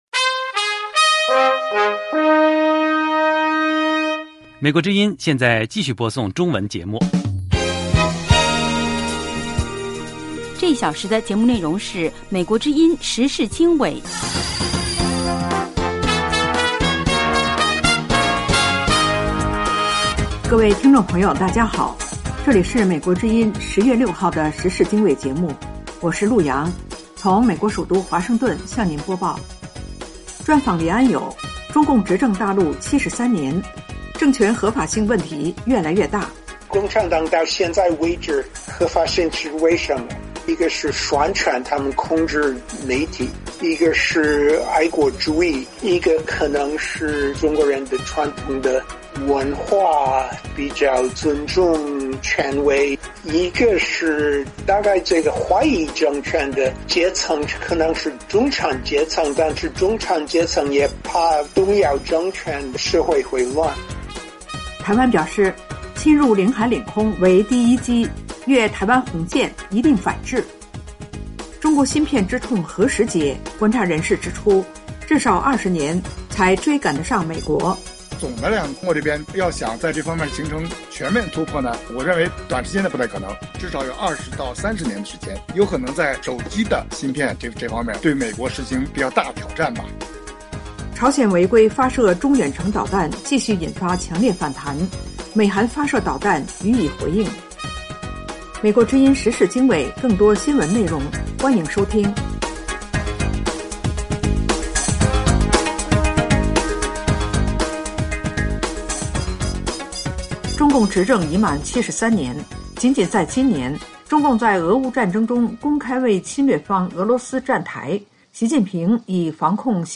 时事经纬(2022年10月6日)：1/专访黎安友（2）：中共执政大陆73年，政权合法性问题越来越大。2/台湾：侵入领海领空为“第一击” 越台湾“红线”一定反制。